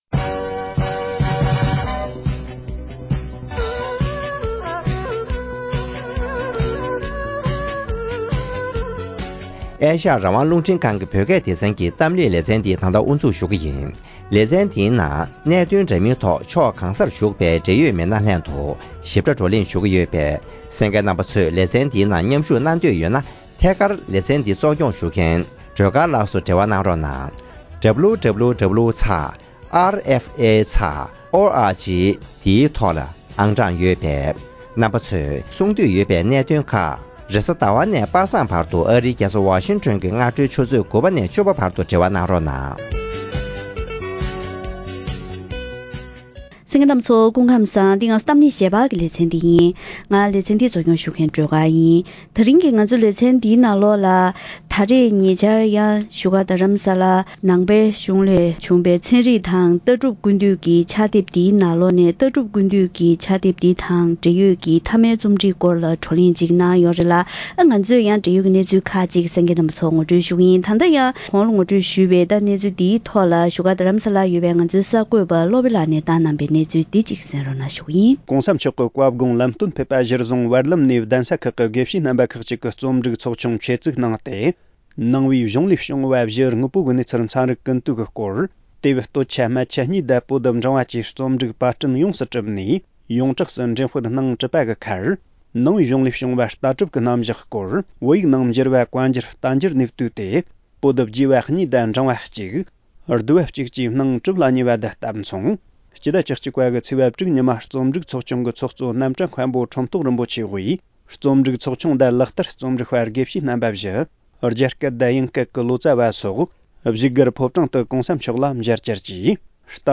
ཕྱག་དེབ་འདི་རྣམས་ཀྱི་རིན་ཐང་སོགས་ཀྱི་སྐོར་ལ་འབྲེལ་ཡོད་དང་ལྷན་དུ་བཀའ་མོལ་ཞུས་པ་ཞིག་གསན་རོགས་གནང་།།